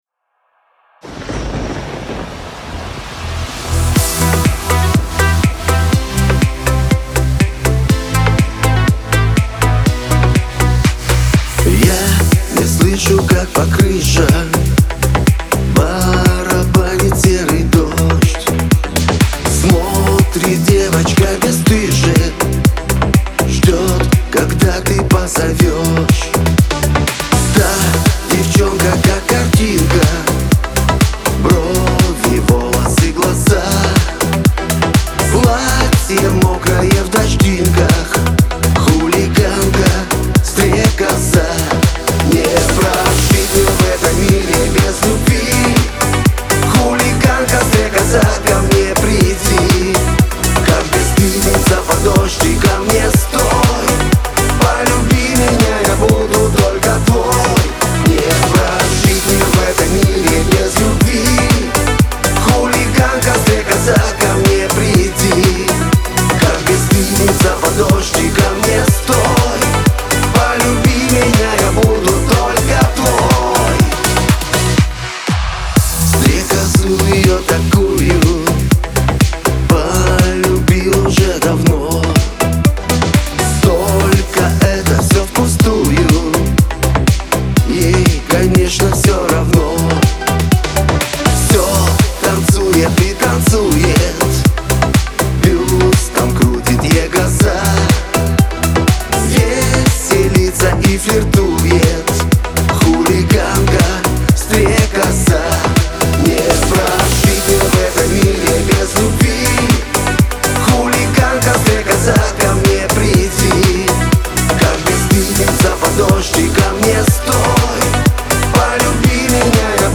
pop , Шансон
Лирика